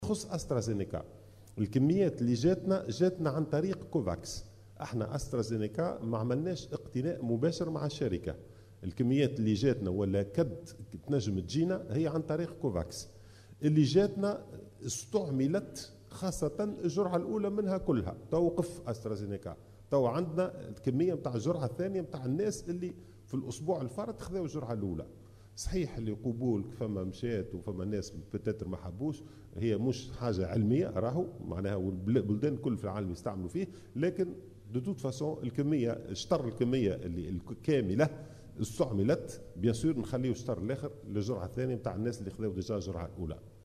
وأضاف خلال ندوة صحفية انعقدت اليوم الجمعة، أن تونس لم تقم بشراء مباشر من الشركة المُصنعة لهذا اللقاح وإنما تحصلت عليه في إطار مبادرة "كوفاكس".